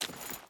Water Chain Run 4.ogg